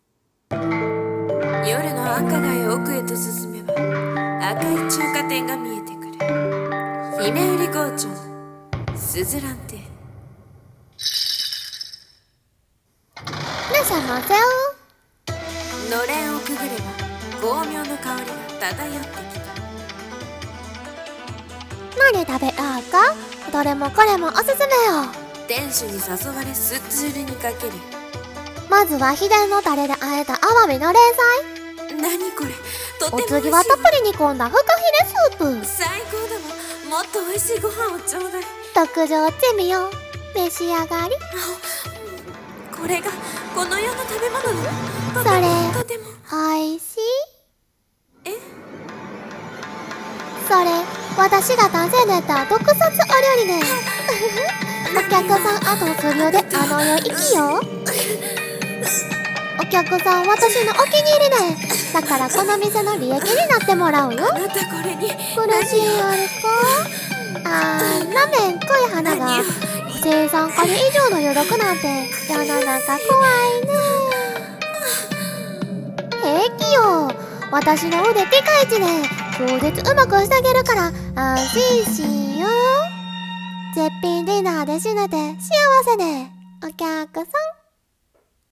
【声劇】珍味の鈴蘭中華店